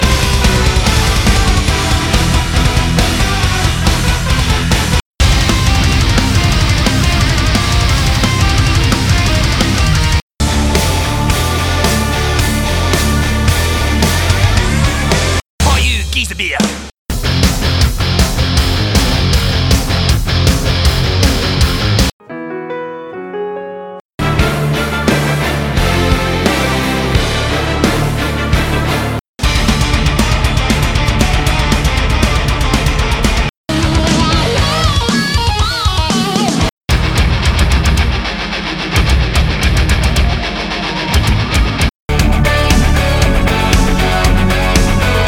Wie bei unserem ersten Cache der Reihe, dem "Schweren Metall" , müsst Ihr Euch den Clip mit den Liederschnipseln anhören.